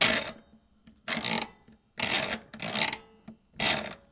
Washing Machine Knob Turn Sound
household
Washing Machine Knob Turn